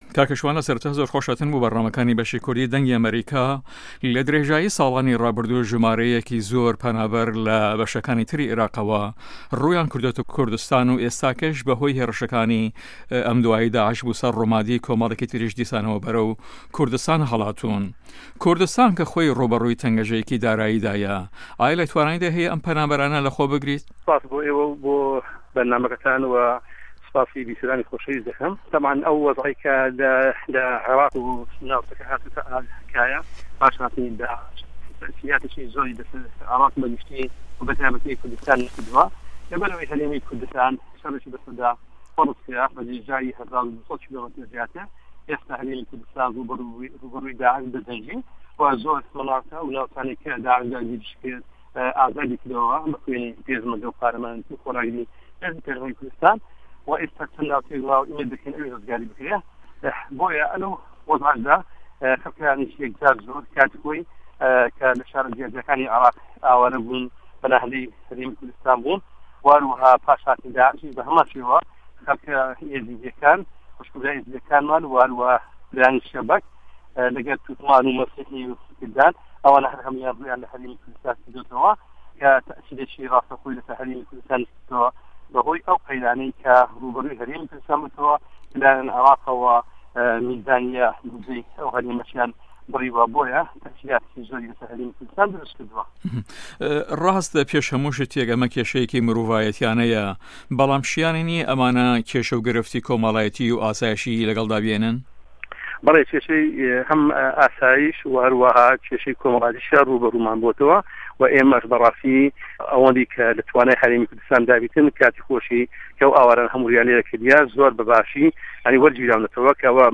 شوان شێخ ئه‌حمه‌د په‌رله‌مانتاری فراکسیۆنی پارتی له‌ په‌رله‌مانی هه‌رێمی کوردستان و سه‌رۆکی لیژنه‌ی ناوچه‌ کوردستانیه‌کانی ده‌ره‌وه‌ی ئیداره‌ی هه‌رێم له‌ هه‌ڤپه‌یڤینێکدا له‌گه‌ڵ به‌شی کوردی ده‌نگی ئه‌مه‌ریکا ده‌ڵێت"ئه‌و په‌نابه‌رانی له‌ شوێنه‌ جۆرا و جۆره‌کانه‌وه‌ روویان له‌ هه‌رێمی کوردستان کردووه‌ کاریگه‌ریه‌کی راسته‌ و خۆ له‌سه‌ر هه‌رێمه‌که‌دا کردووه‌ به‌ هۆێ ئه‌و قه‌یرانه‌ی روبه‌رووی بوه‌ته‌وه‌ له‌ لایه‌ن عێراقه‌وه‌ که‌ میزانیه‌ی بۆدجه‌ی هه‌ریمیان بریوه‌.